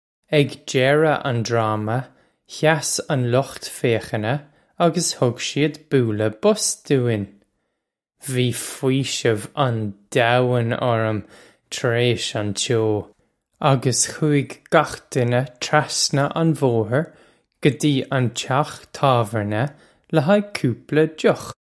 Listen to the pronunciation here. This comes straight from our Bitesize Irish online course of Bitesize lessons.